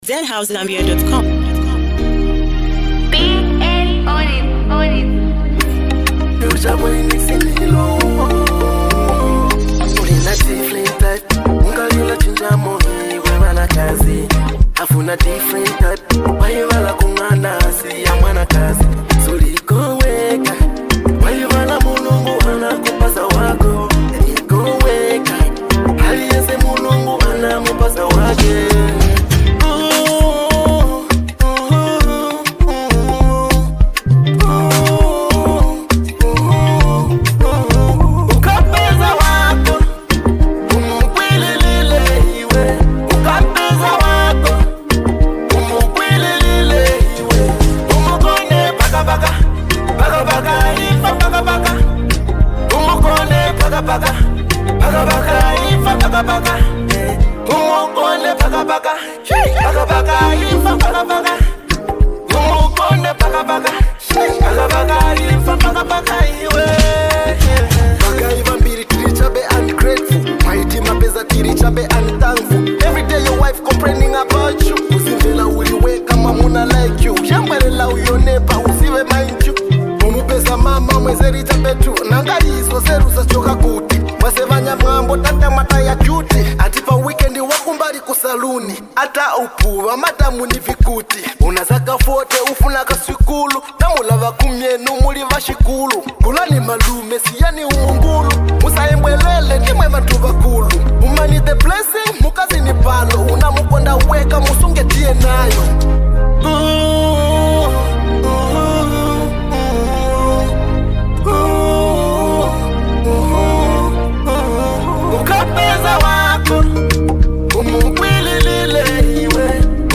soulful